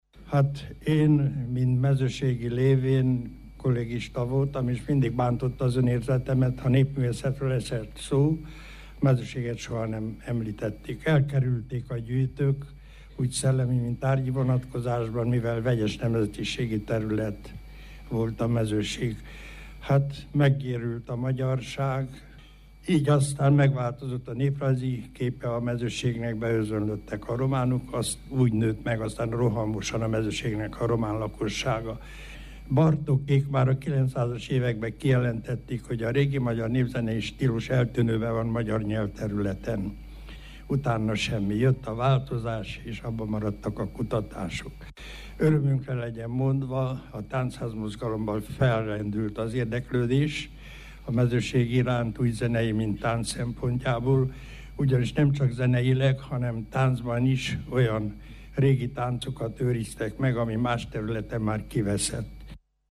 2014-ben, Jobbágytelkén készített interjút Kallós Zoltánnal.